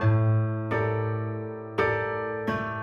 Index of /musicradar/gangster-sting-samples/85bpm Loops
GS_Piano_85-A2.wav